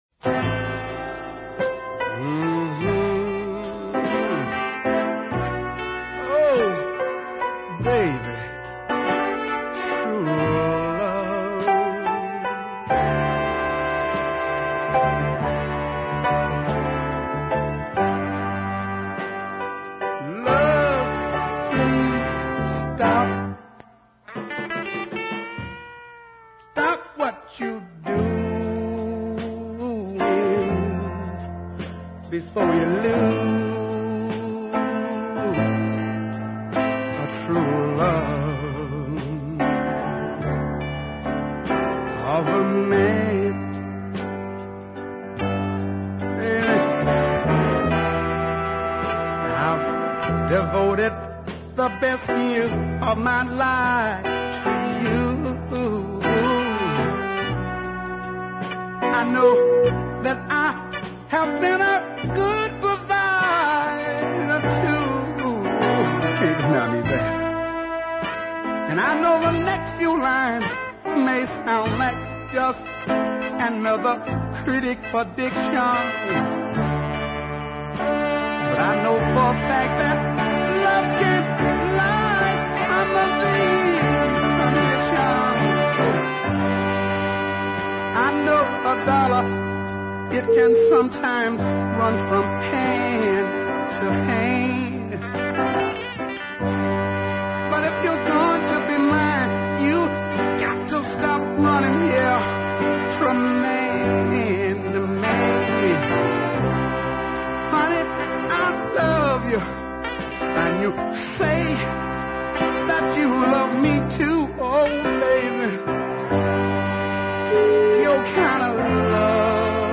especially the way he handles the large horn sections.